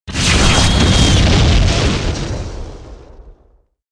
med explo 4.wav